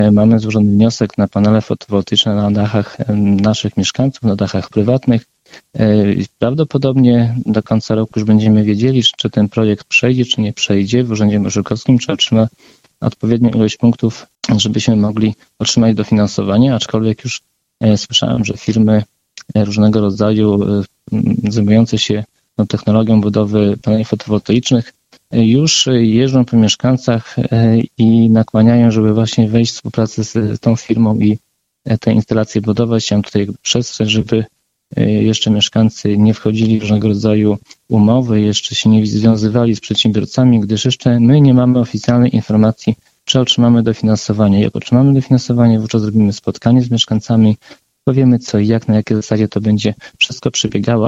Mijający rok podsumował w środę (16.12) w Radiu 5 Zbigniew Mackiewicz, wójt gminy Suwałki.